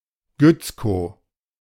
Gützkow (German pronunciation: [ˈɡʏt͡sˌkoː]
De-Gützkow.ogg.mp3